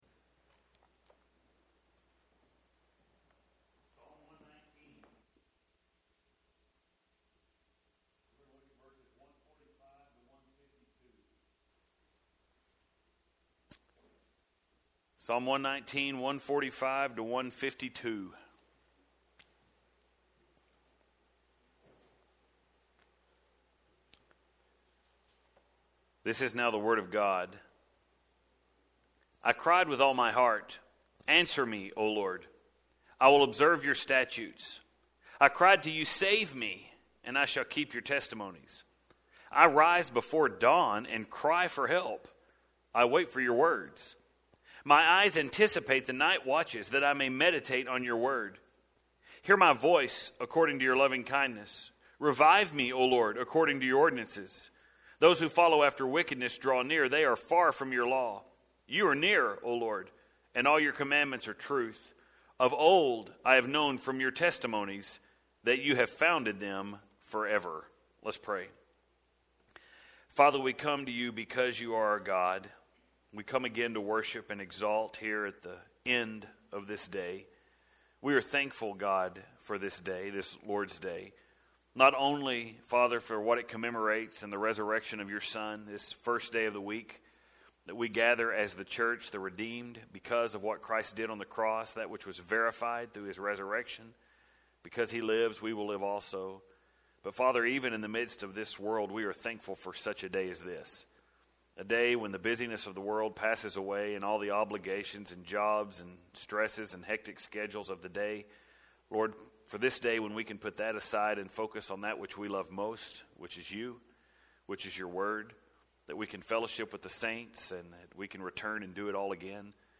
If you’ve been listening to those sermons from the first time through Then you know that the last time we studied this stanza […]